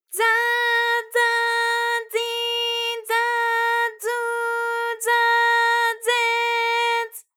ALYS-DB-001-JPN - First Japanese UTAU vocal library of ALYS.
za_za_zi_za_zu_za_ze_z.wav